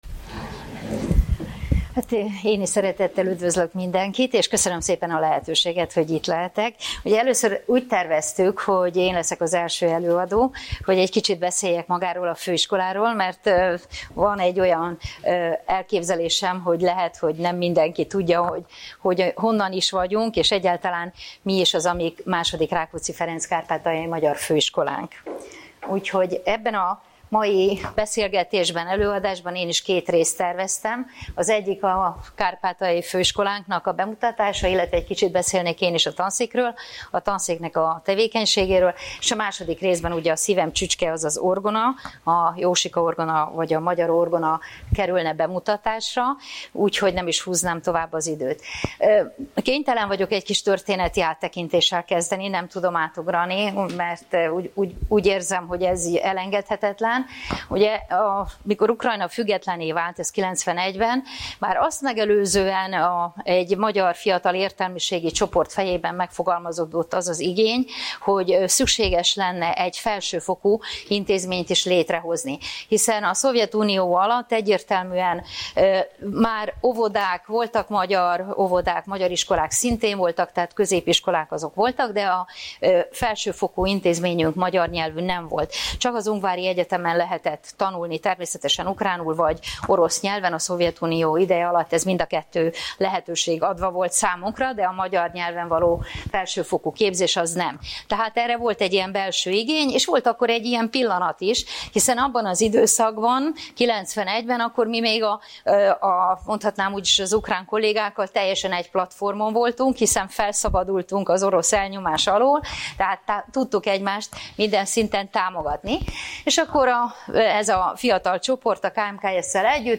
Előadások, konferenciák
(lecturer)